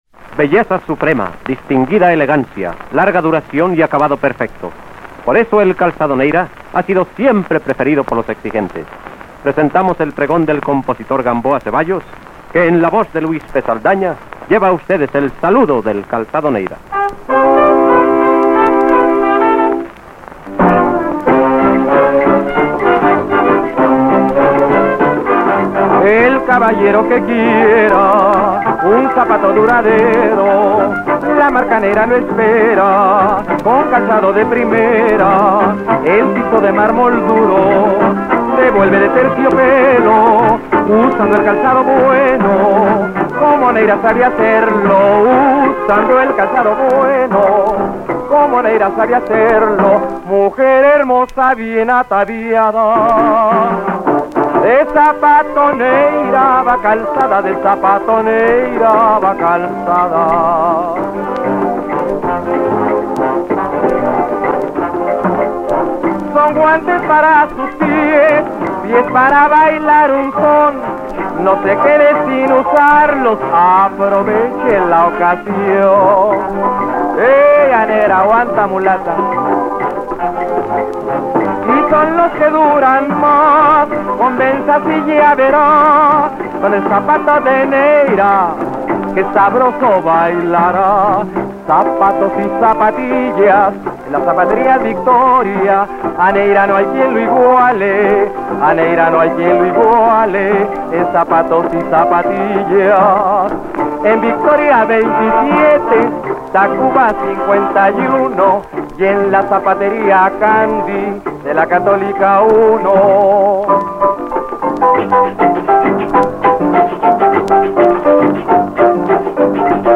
pregón